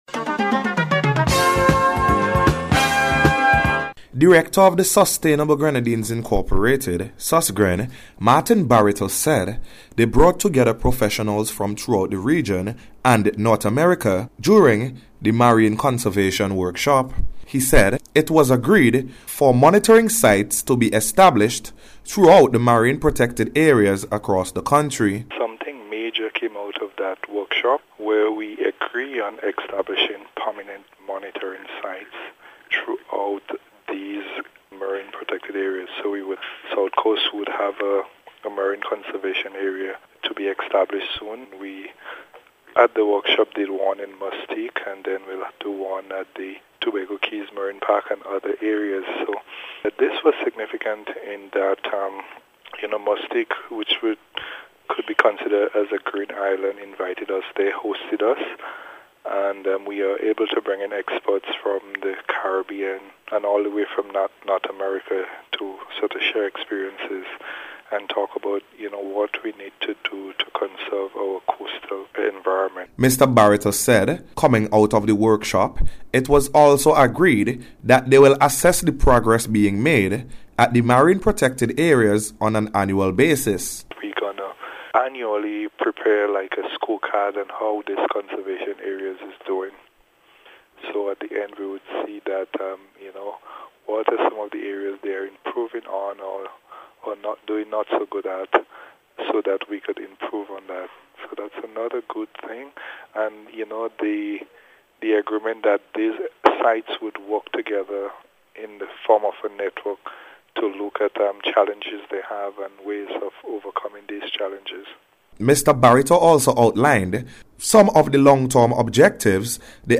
SUSGREN-COASTLINE-WORK-REPORT.mp3